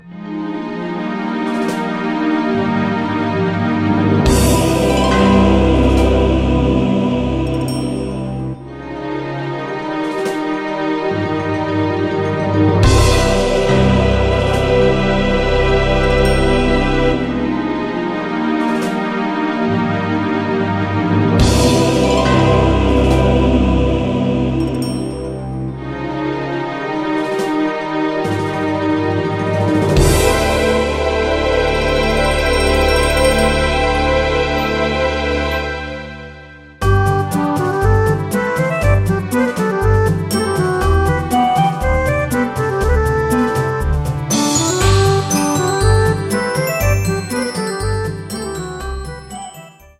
ゲーム全体を締めくくる癒しの曲としてお願いしました。
コミカルな曲は少なめで、重めの曲が多い傾向にあります。